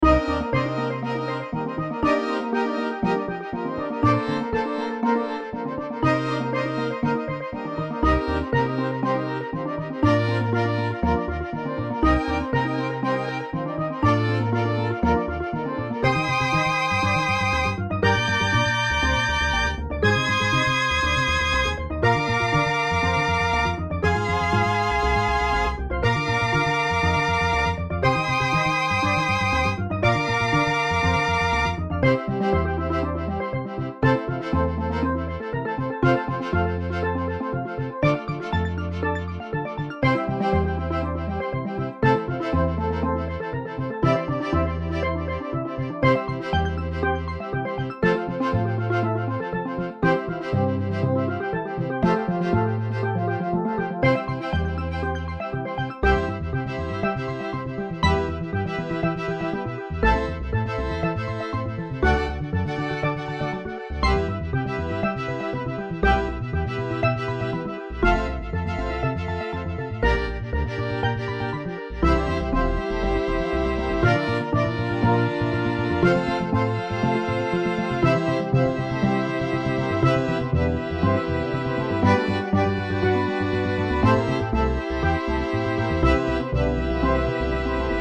スティールドラム、アコースティックベース、チェロ